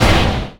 IMPACT_Generic_16_mono.wav